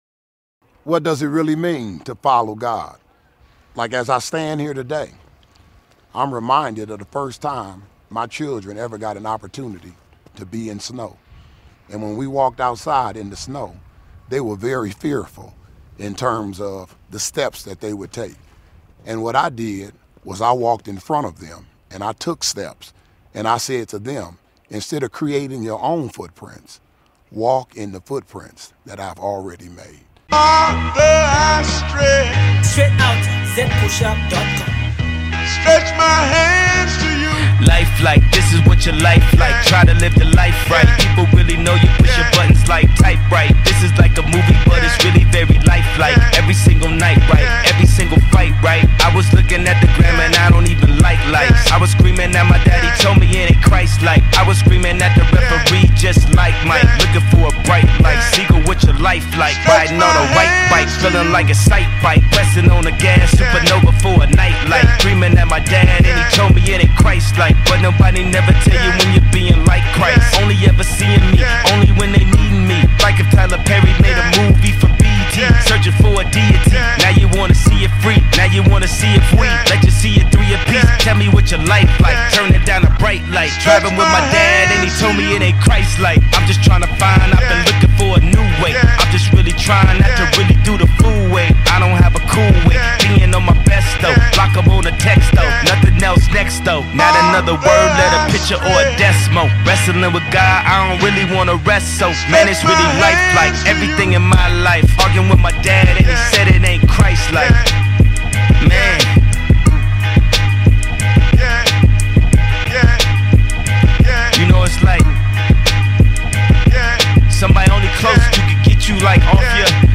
driving song